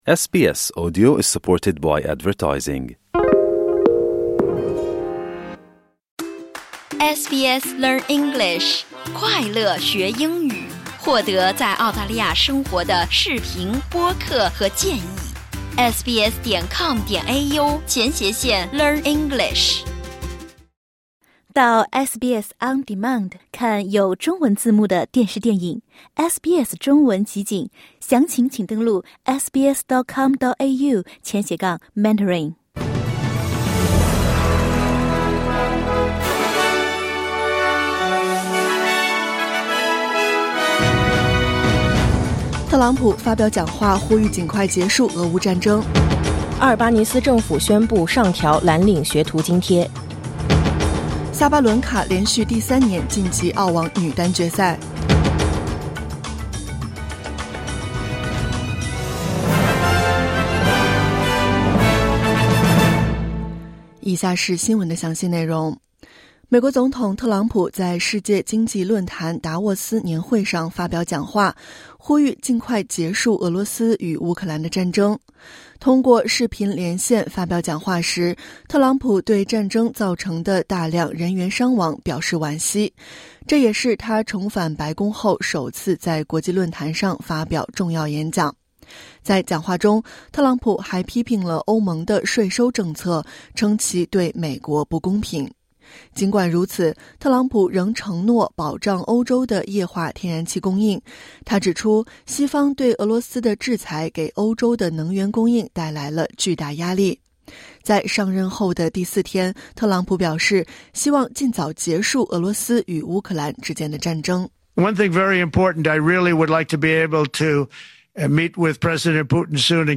SBS早新闻（2025年1月24日）